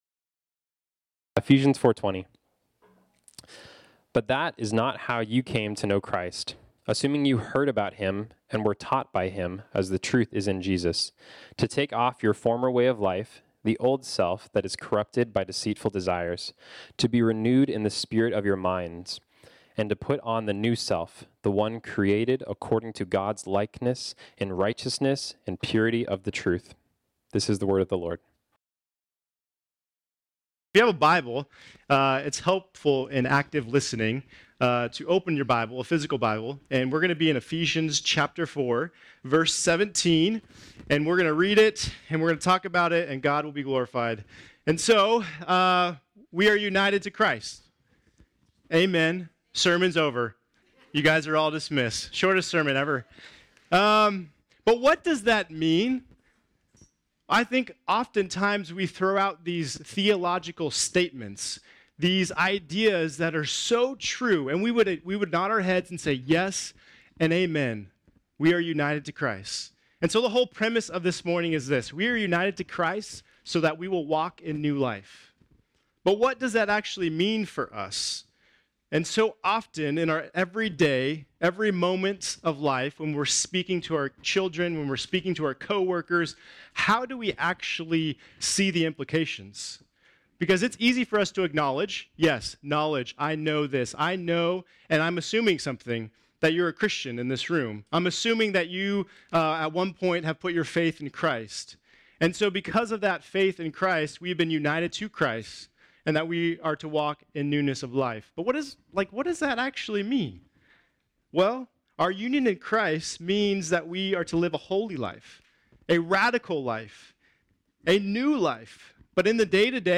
This sermon was originally preached on Sunday, November 5, 2023.